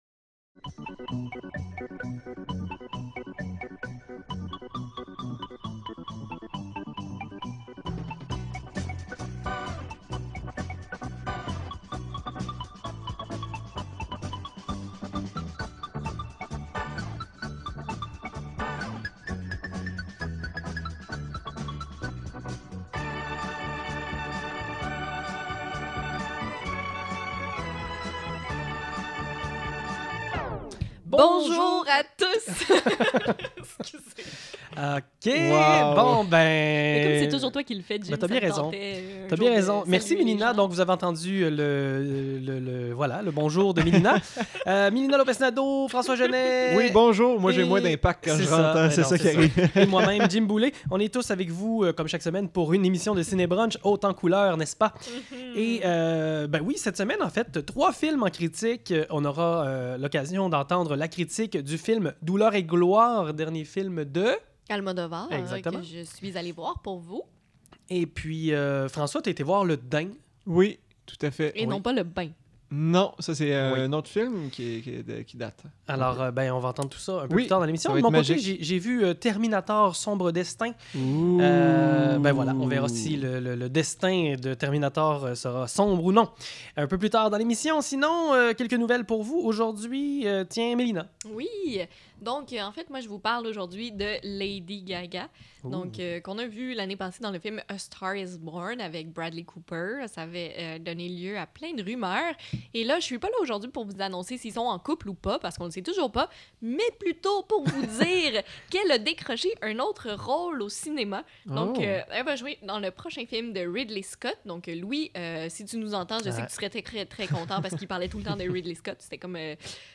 Trois co-animateurs dynamiques vous présentent des critiques de films, discussions sur le cinéma, sorties en salle et plus encore!